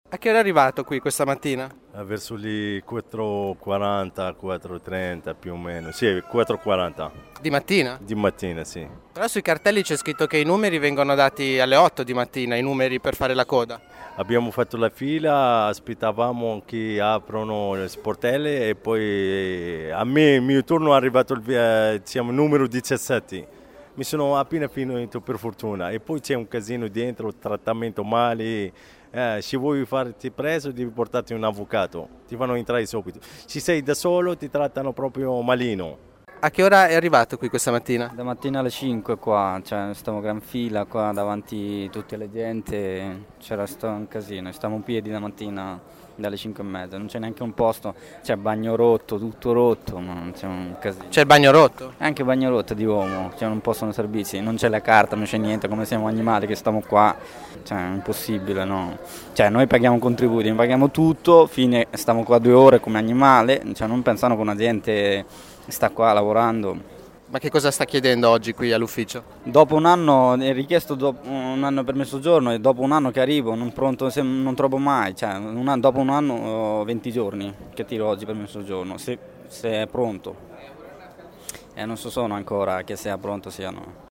Ascolta due migranti arrivati all’ufficio immigrazione alle 5 di mattina